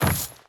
Footsteps / Wood / Wood Chain Land.wav
Wood Chain Land.wav